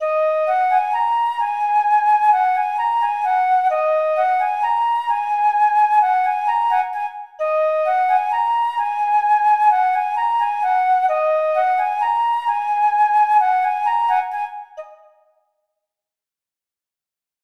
标签： 130 bpm Trap Loops Flute Loops 4.97 MB wav Key : G
声道立体声